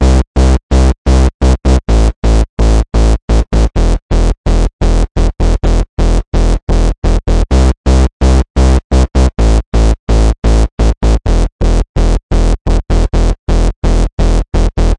力量型低音电声房合成器（SAW+SQUARE）。
描述：电声低音钩的进展。以此为基础来制作电音屋曲目。节奏是128 bpm，所以下载它并开始工作吧！
标签： 运动 发展 逐行 128 合成器 BPM 房子 舞蹈 电子 低音
声道立体声